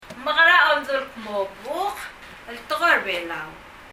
Ng mekera omdu el kmo ・・・ el Tekoi er a Belau? [ŋ mək(g)ərə ɔmðu l kmɔ ・・・ ɛl təkɔi ɛr ə bɛlau]
HowDoYouSayBookInBelau.mp3